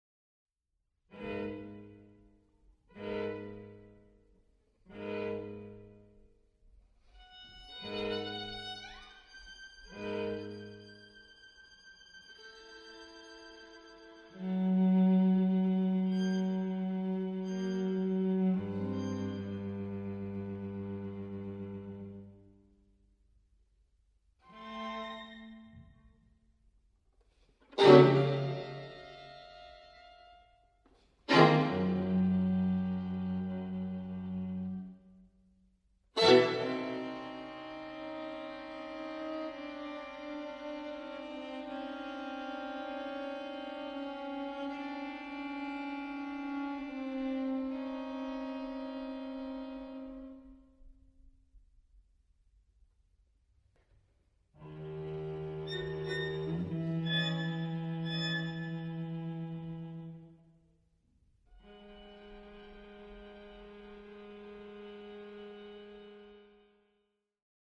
Violine
Viola
Violoncello
Rec: Sept. 1998, St. Petersburg